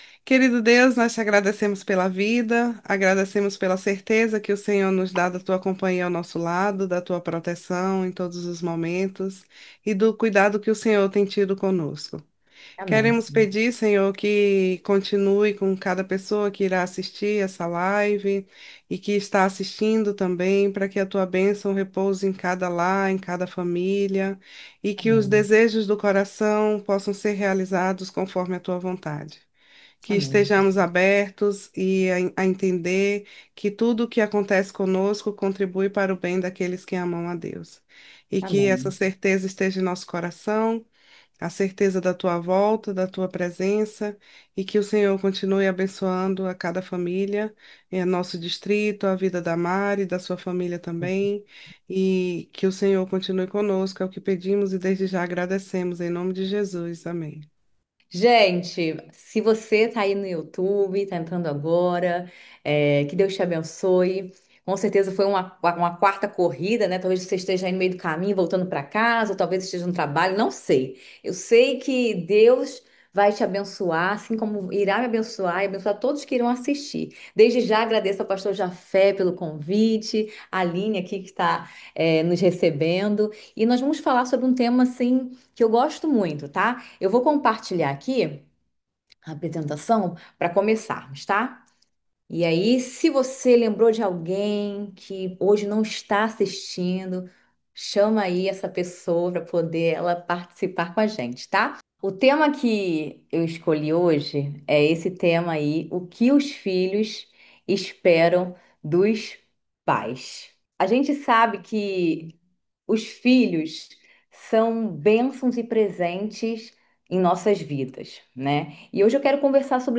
A Hora Tranquila é um devocional semanal.